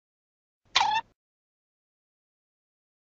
Cartoon Sound Effects MP3 Download Free - Quick Sounds